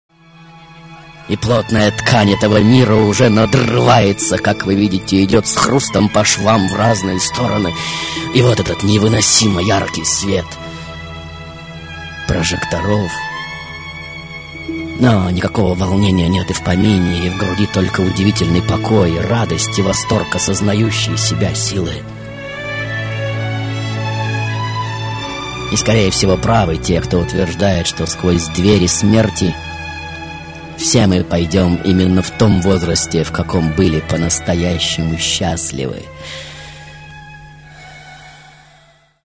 голосовые
Радиопередача